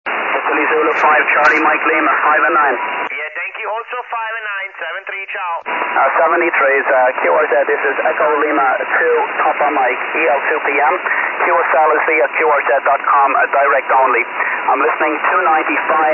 Here there are few soundclips of famous Dxpeditions and not of last years on HF and 6 meter,
i hope to add more in the future, now i try to record all my qso ! Hi !
SSB